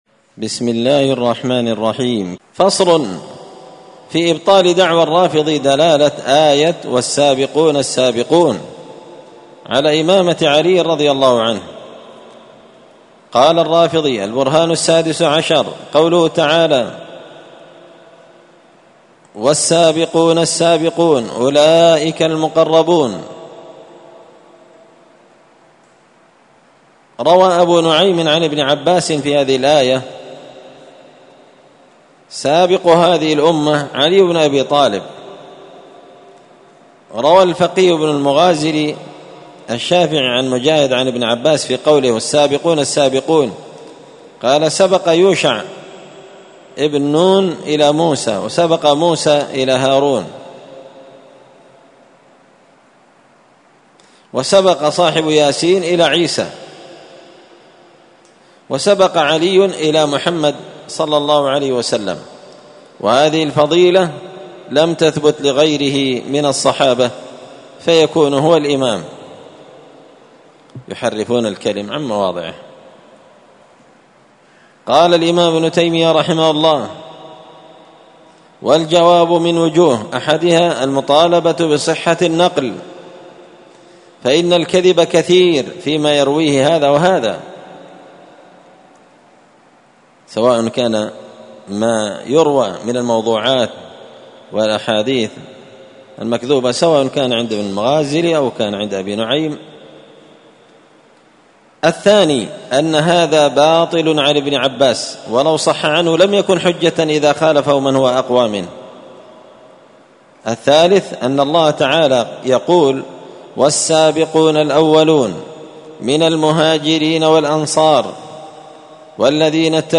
الأحد 11 صفر 1445 هــــ | الدروس، دروس الردود، مختصر منهاج السنة النبوية لشيخ الإسلام ابن تيمية | شارك بتعليقك | 60 المشاهدات
مسجد الفرقان قشن_المهرة_اليمن